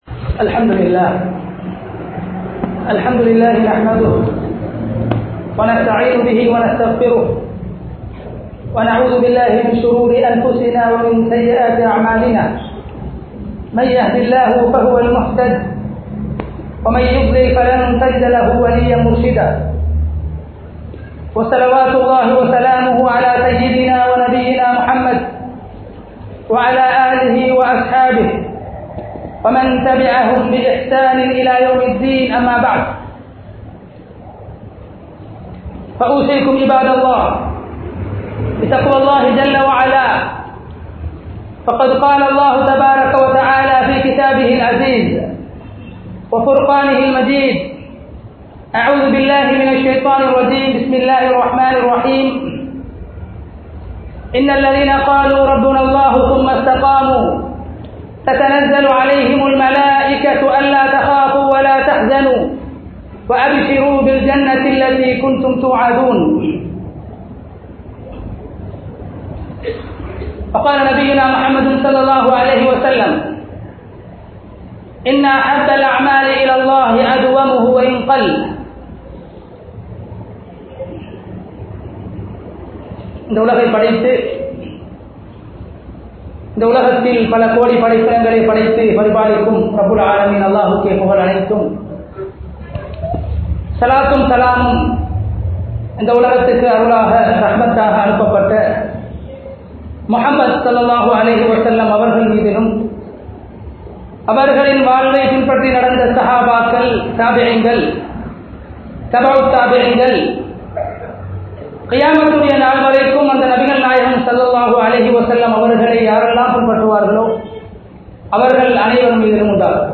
Amalahalai Thadukkum 05 Vidayangal (அமல்களை தடுக்கும் 05 விடயங்கள்) | Audio Bayans | All Ceylon Muslim Youth Community | Addalaichenai